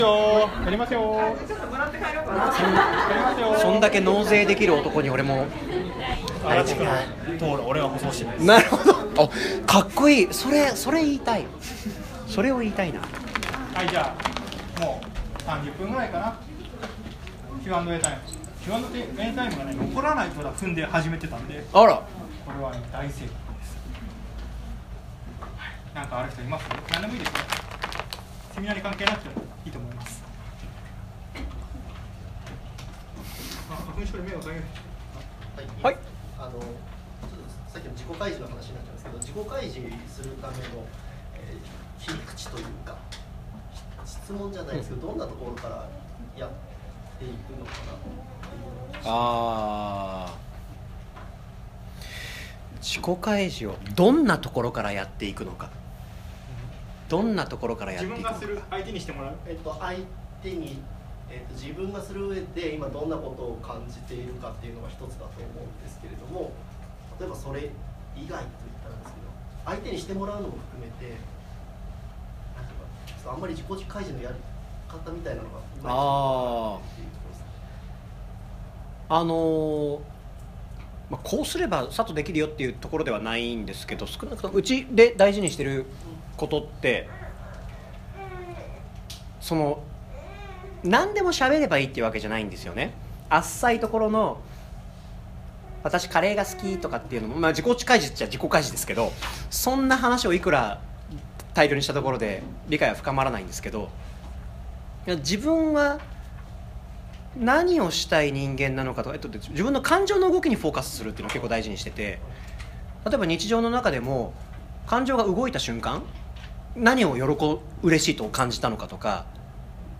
時代と人間のティステインぐセミナー 質疑応答 - Pay it forward
時代＆人間のテイスティングセミナーPart.3.m4a